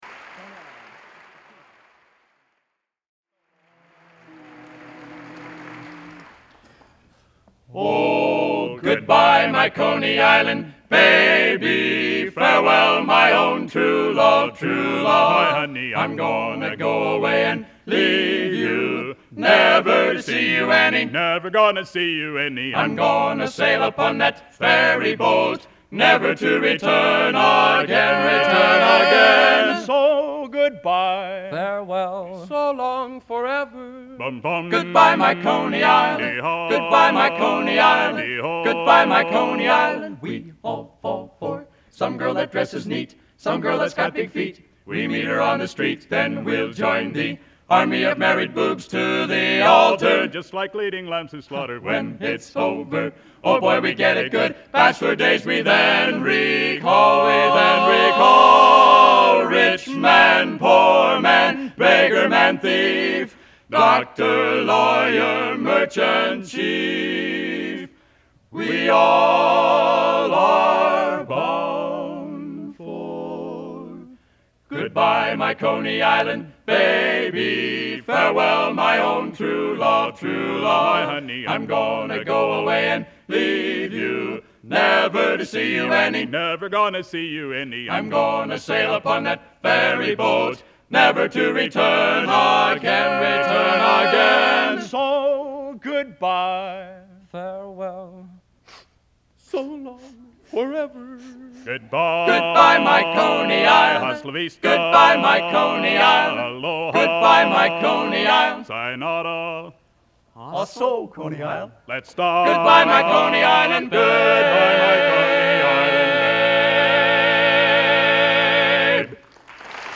Location: West Lafayette, Indiana
Genre: Barbershop | Type: End of Season
64-EOS-3.04-Coney-Island-Barbershop.mp3